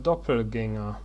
1. ^ a b From German Doppelgänger, pronounced [ˈdɔpl̩ˌɡɛŋɐ]
De-doppelgaenger.ogg.mp3